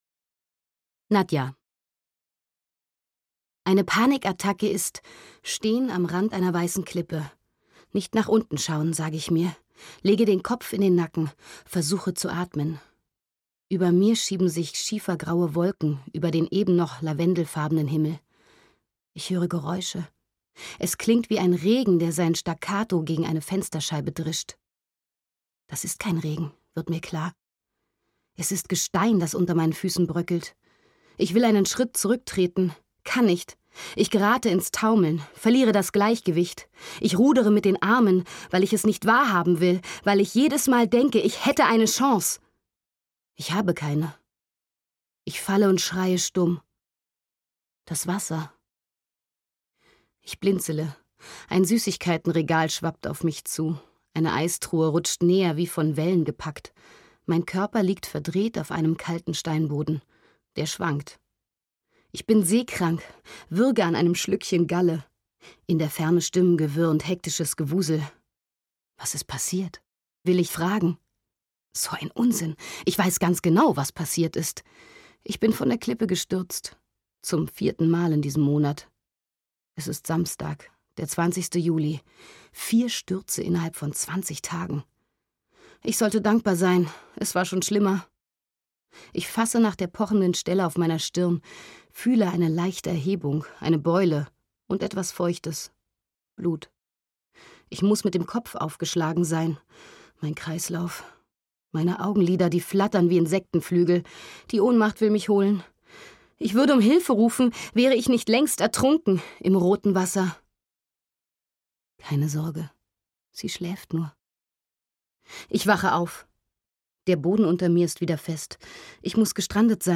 Marta schläft (DE) audiokniha
Ukázka z knihy